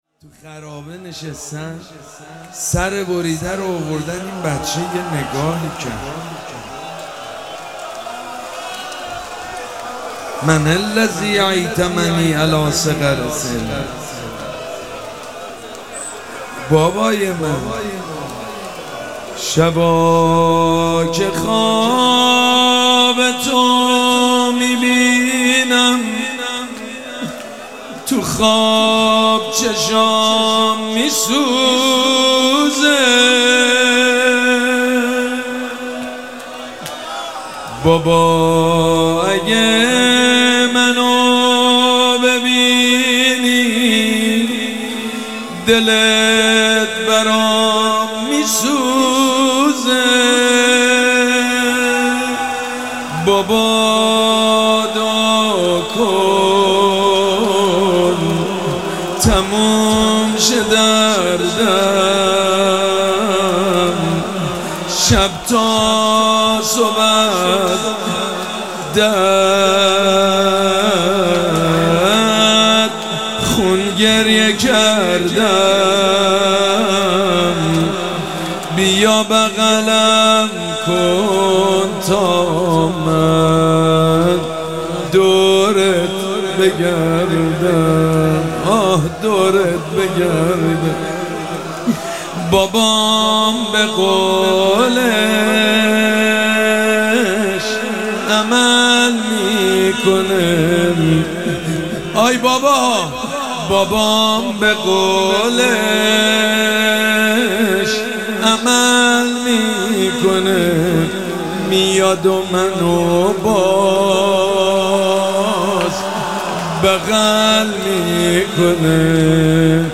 شب سوم مراسم عزاداری اربعین حسینی ۱۴۴۷
روضه
مداح
حاج سید مجید بنی فاطمه